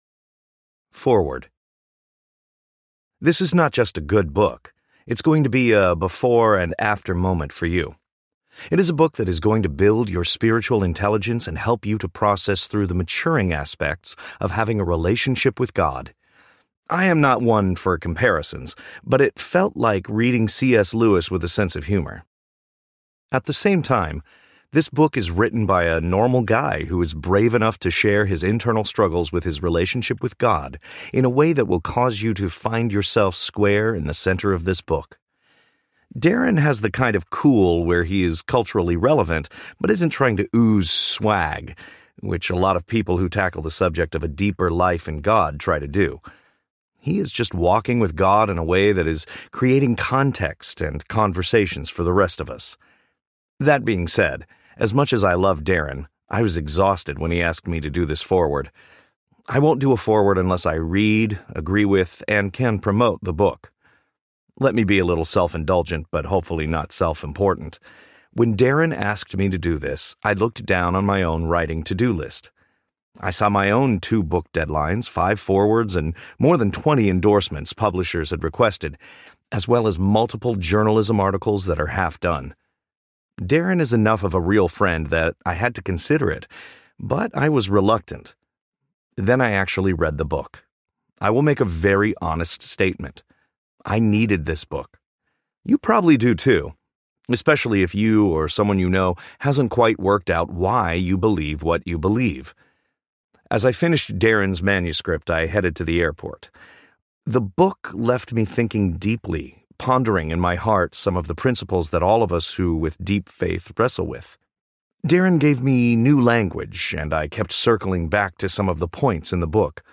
Chasing a God You Don’t Want to Catch Audiobook
Narrator
6.2 Hrs. – Unabridged